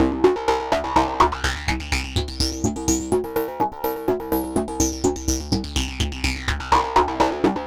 tx_synth_125_feedback_CD2.wav